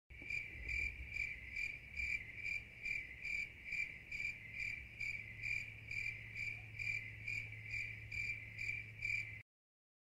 Cricket Sounds Legacies Bouton sonore